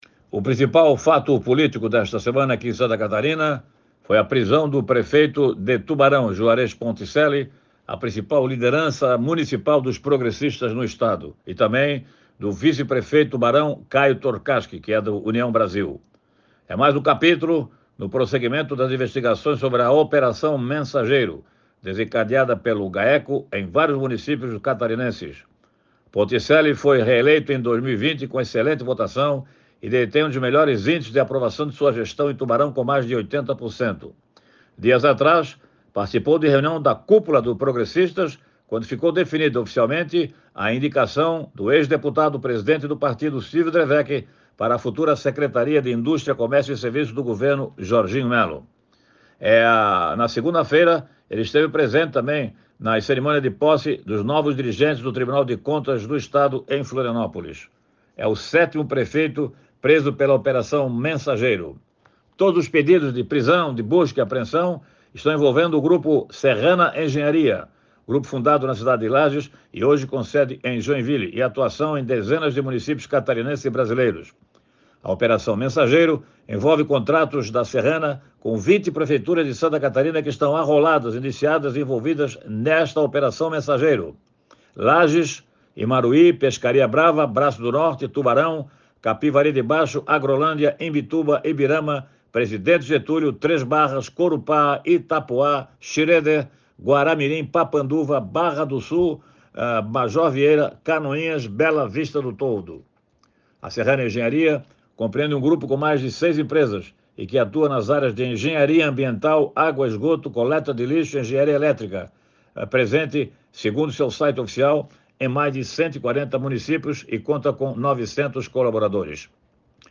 Jornalista comenta que, ao todo, 22 mandados de prisão foram cumpridos pela justiça